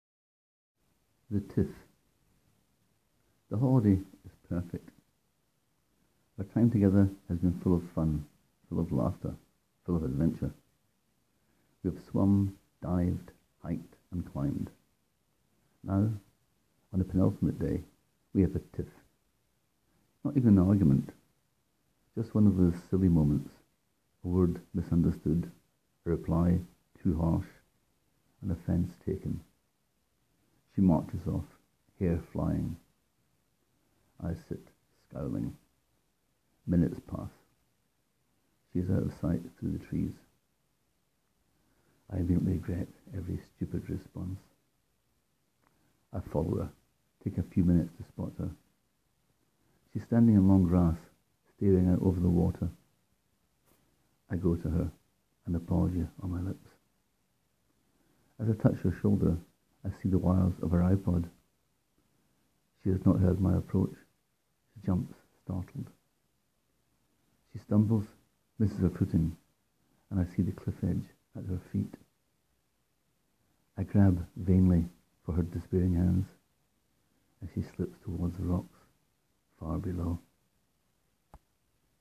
And hearing them with a sexy Scottish accent doesn’t hurt.